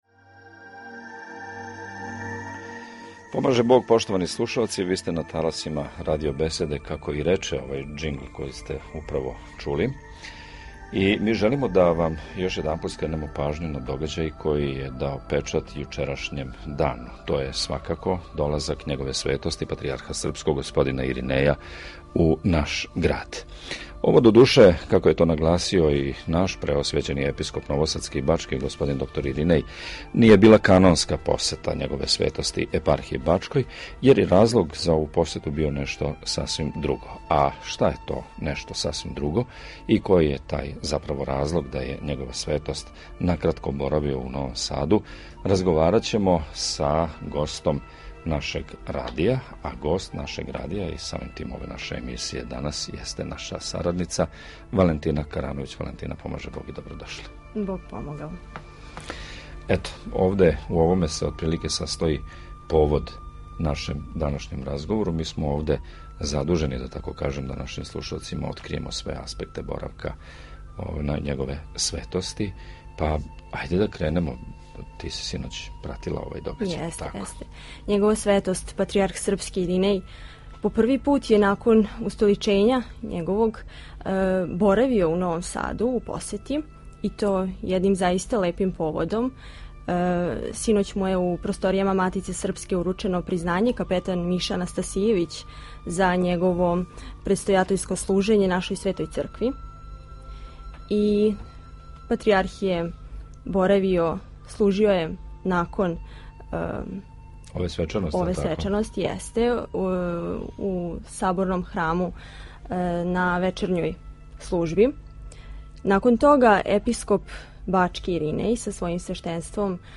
• Специјална емисија Радио Беседе о посети Патријарха Иринеја: